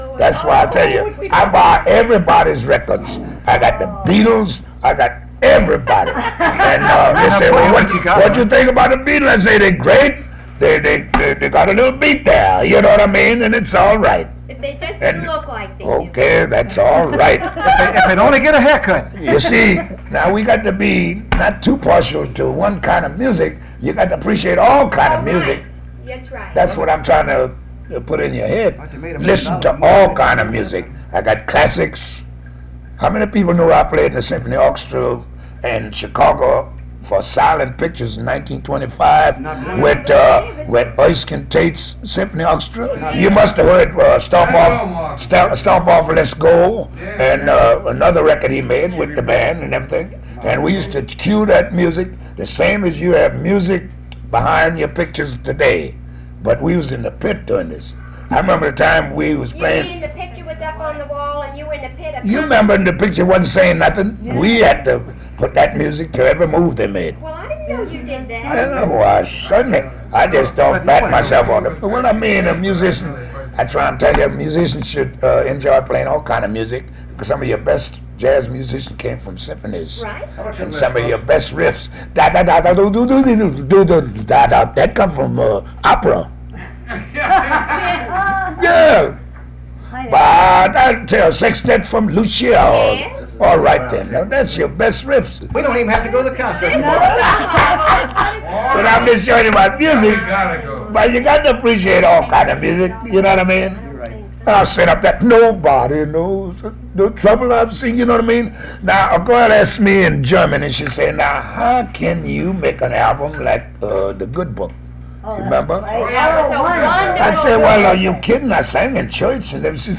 In that clip alone, Louis mentions enjoying the Beatles, reminisces about playing classical music with Erskine Tate at the Vendome Theater in Chicago, sings a snippet of Verdi’s “Quartet” from Rigoletto and defends his choice to record Louis and the Good Book, his 1958 album of spirituals.